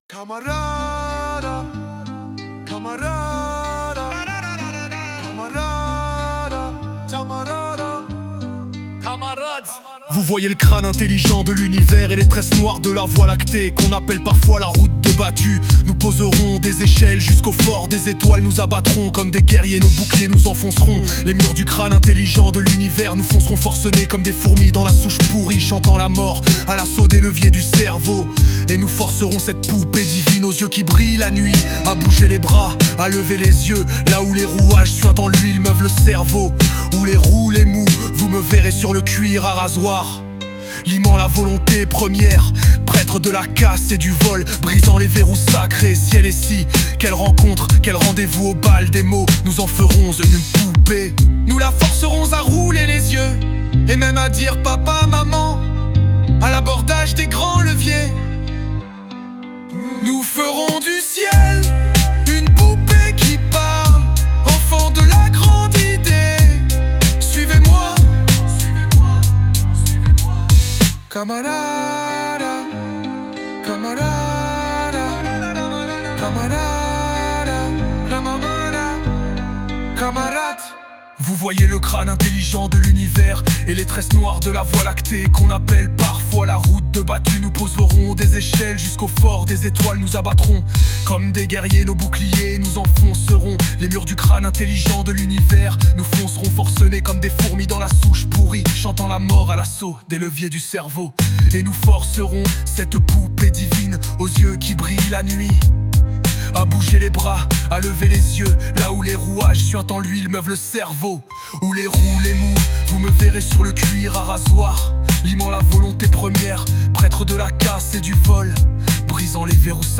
Texte mis en voix et musique par l'IA SUNO